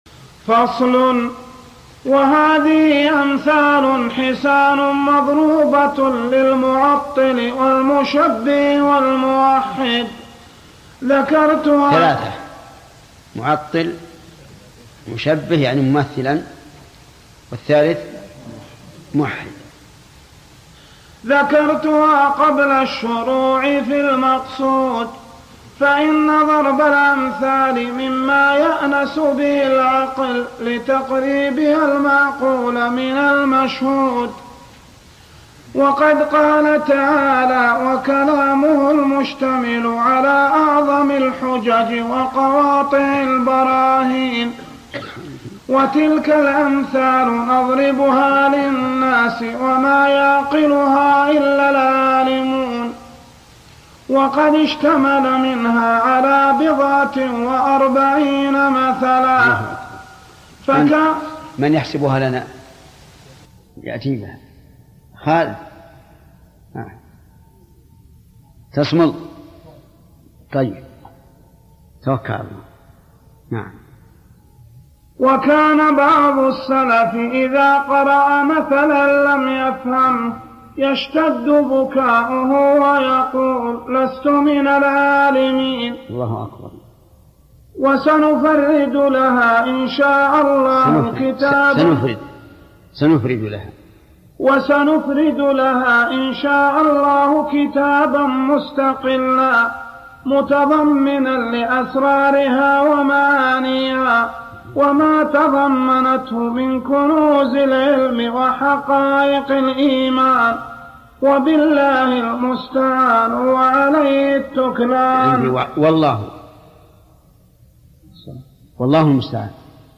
سبب ضرب ابن القيم أمثلة للمعطلة والمشبه والموحد (قراءة للمتن) - ابن عثيمين